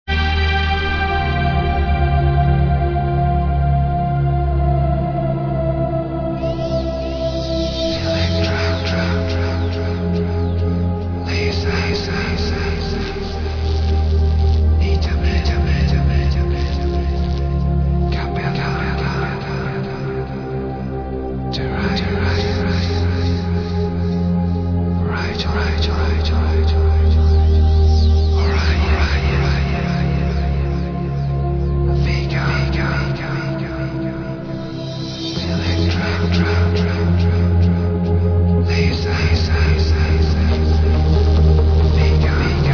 Trance tune